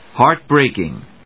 héart・brèaking